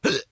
retch1.ogg